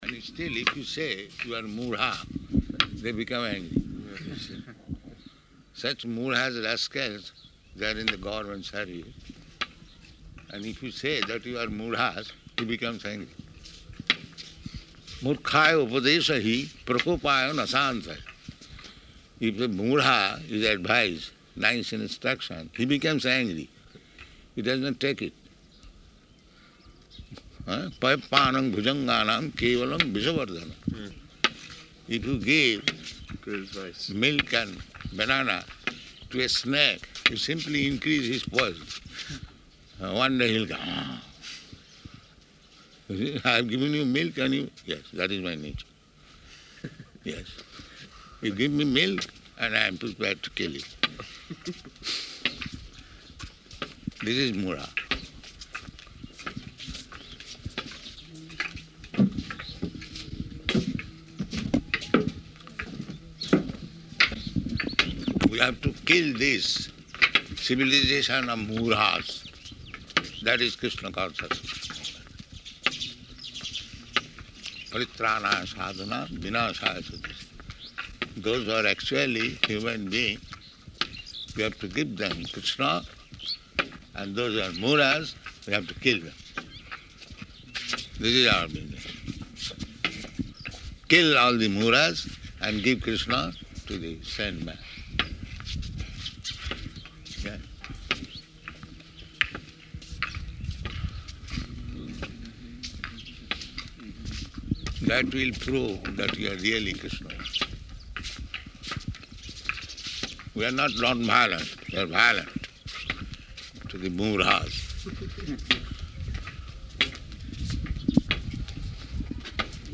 Morning Walk --:-- --:-- Type: Walk Dated: January 21st 1976 Location: Māyāpur Audio file: 760121MW.MAY.mp3 Prabhupāda: Still, if you say, "You are mūḍha, " they become angry.